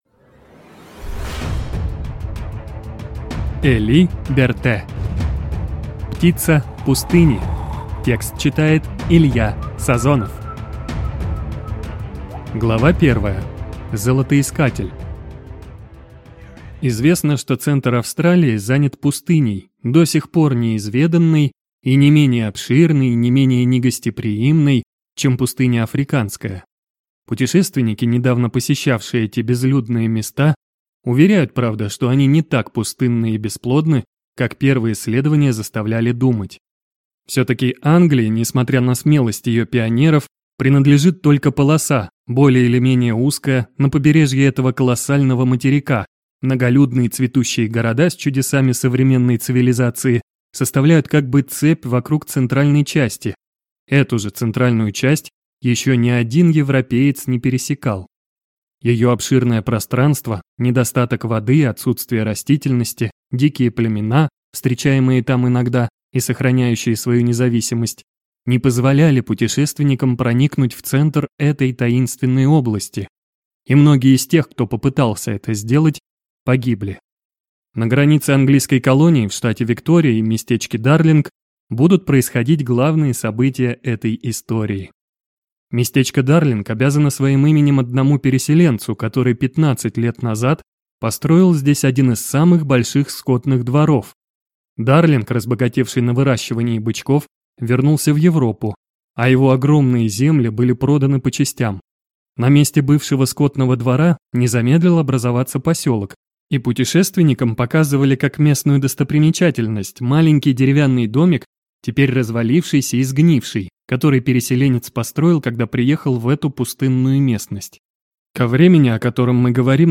Аудиокнига Птица пустыни | Библиотека аудиокниг